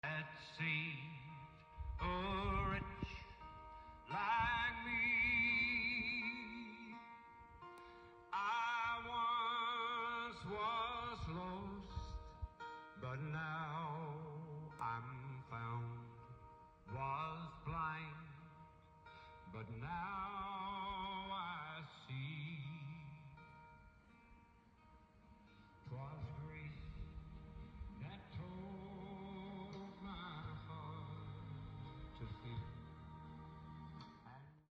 softly drifting through the trees